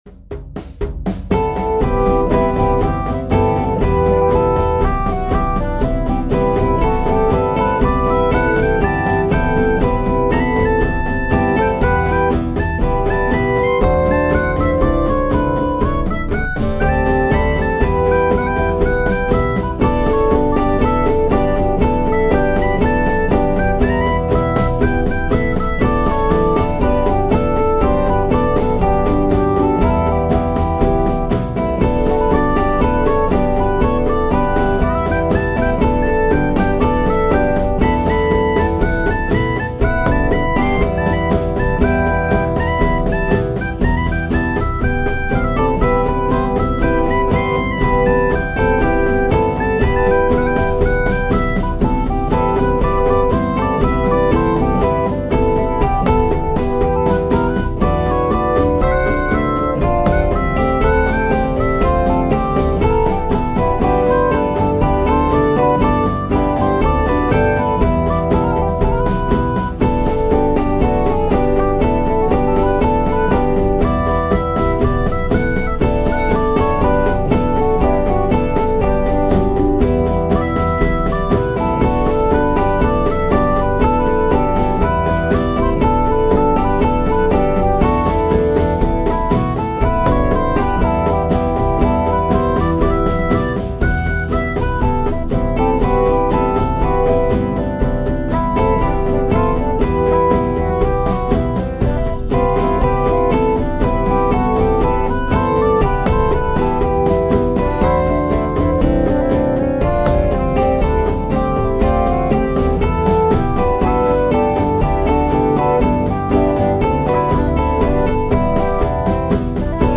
midi-demo 1 midi-demo 2 midi-demo 3